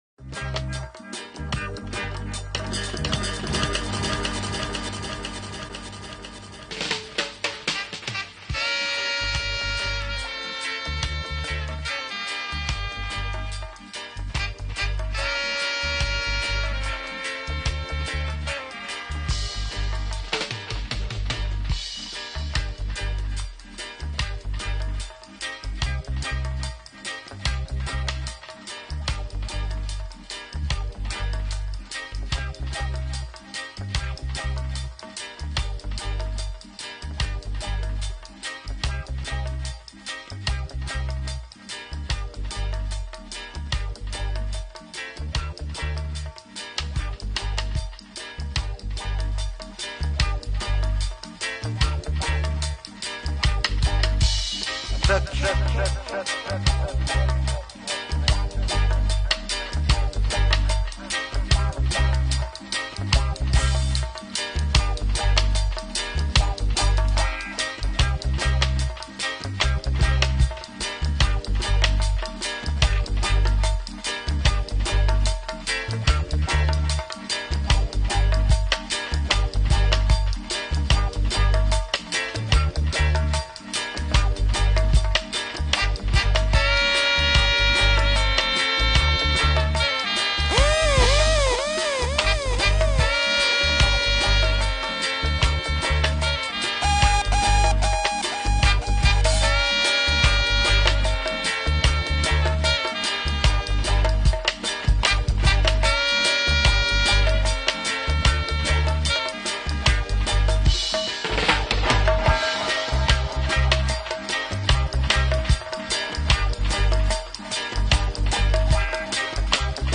NOTES: Facebook muting again 30 mins edited out!!